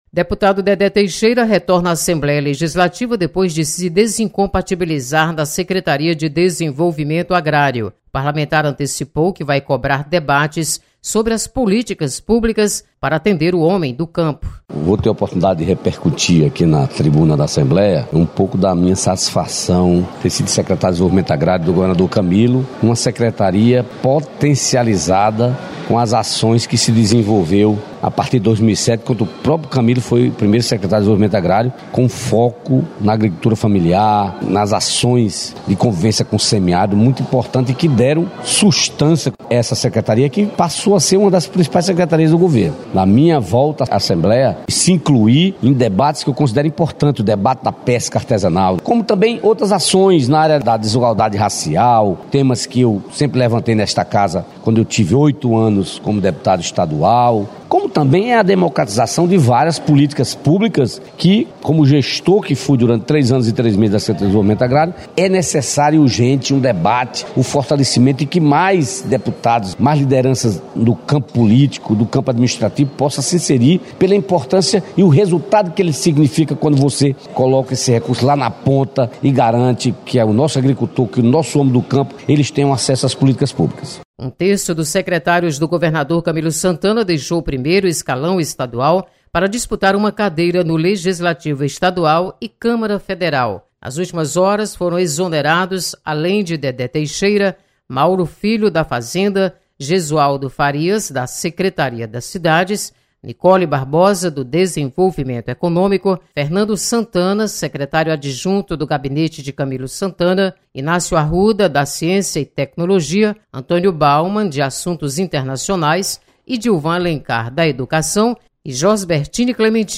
Você está aqui: Início Comunicação Rádio FM Assembleia Notícias Secretários